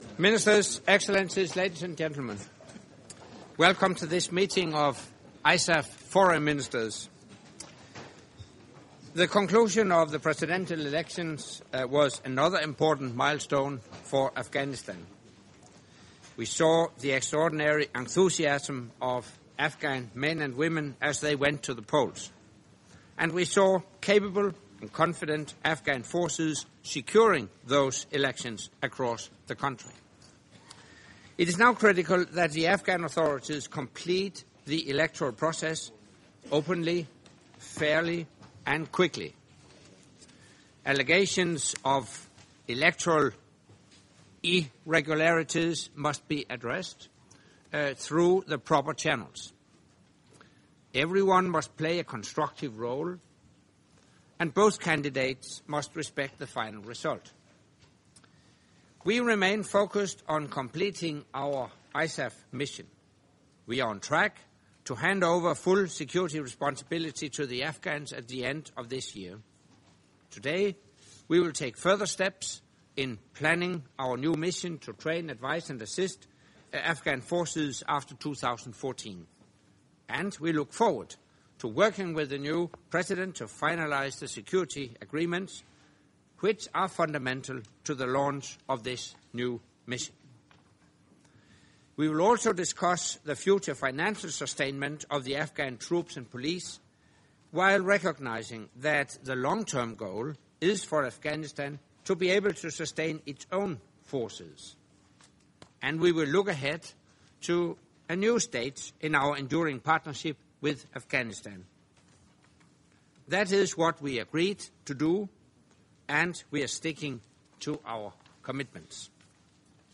FRENCH - Opening remarks by NATO Secretary General Anders Fogh Rasmussen at the meeting of the North Atlantic Council with non-NATO ISAF Contributing Nations at the level of Foreign Affairs Ministers